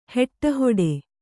♪ heṭṭa hoḍe